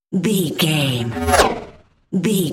Pass by sci fi fast
Sound Effects
Fast
futuristic
intense
pass by
vehicle